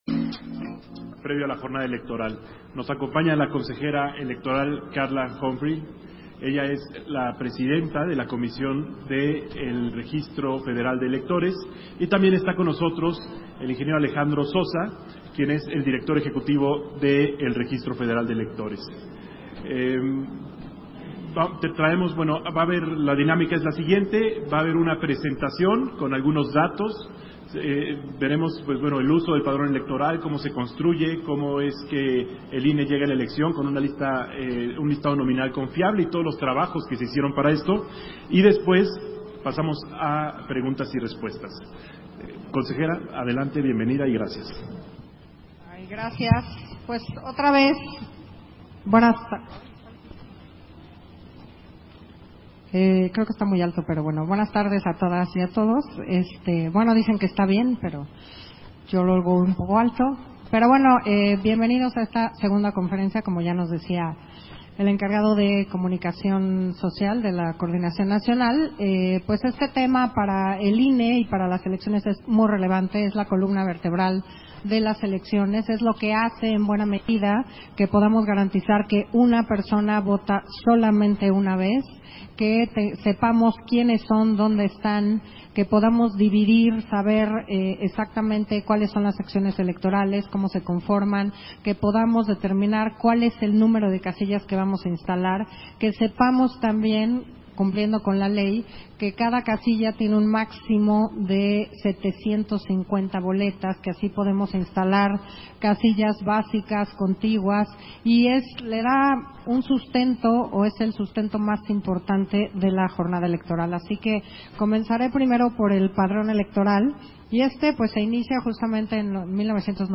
300524_AUDIO_CONFERENCIA-REGISTRO-FEDERAL-DE-ELECTORES - Central Electoral